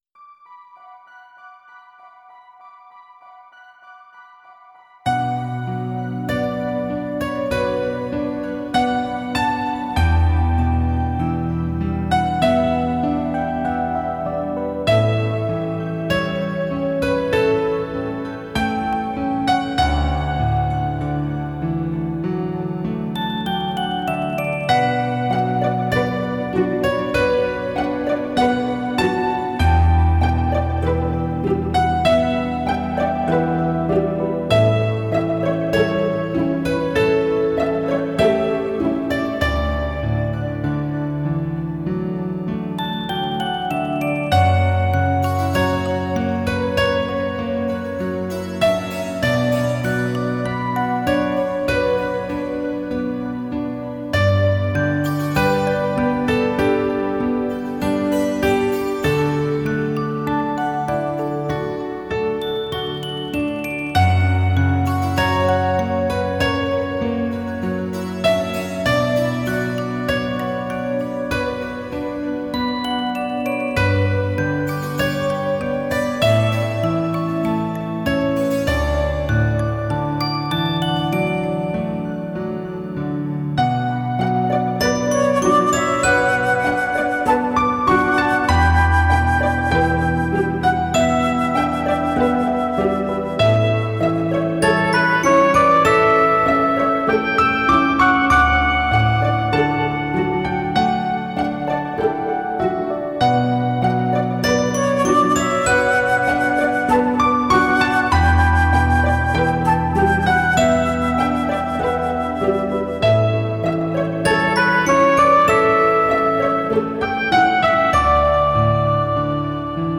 由于碟片原因，音质并非很理想，请谅解。